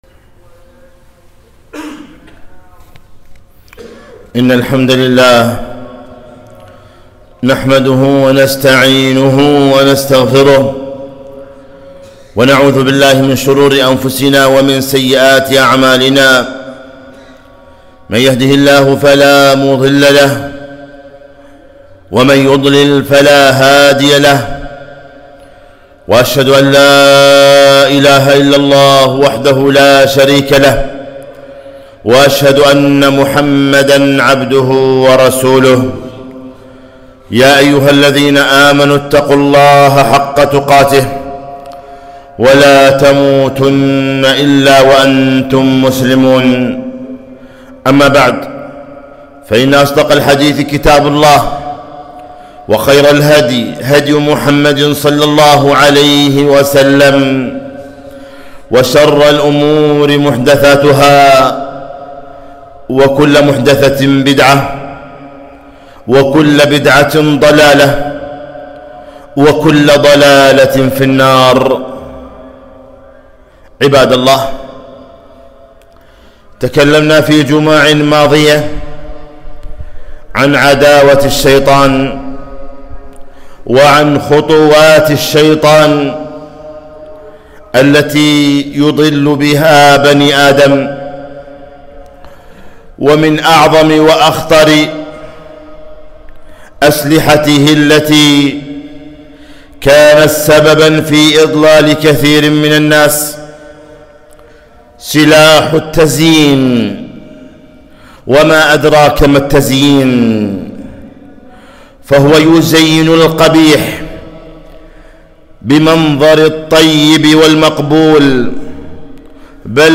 خطبة - احذروا تزيين الشيطان